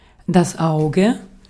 Ääntäminen
Ääntäminen : IPA: [das ˈaʊ̯.ɡə] Tuntematon aksentti: IPA: /ˈaʊ̯.ɡə/ Haettu sana löytyi näillä lähdekielillä: saksa Käännös Substantiivit 1. silmä Muut/tuntemattomat 2. myrskynsilmä Artikkeli: das .